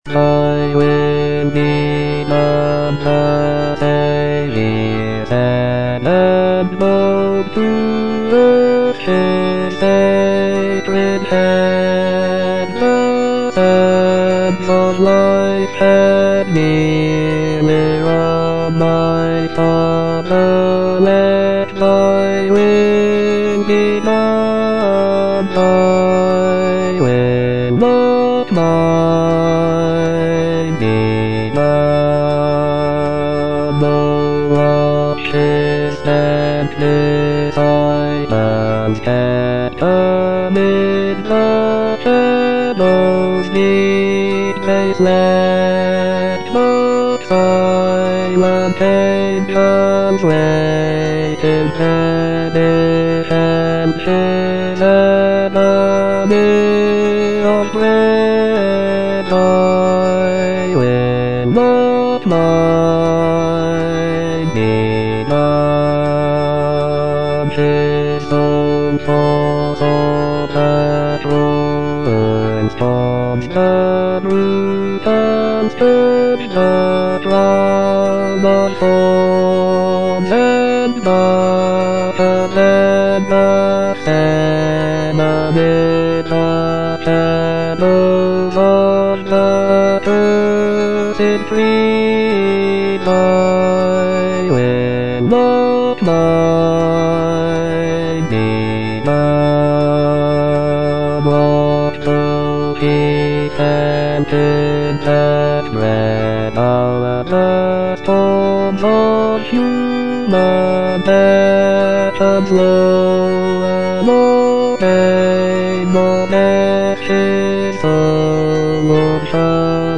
J.H. MAUNDER - OLIVET TO CALVARY 6b. Thy will be done - Bass (Voice with metronome) Ads stop: auto-stop
"Olivet to Calvary" is a sacred cantata composed by John Henry Maunder in 1904. It presents a musical narrative of the events leading up to the crucifixion of Jesus Christ.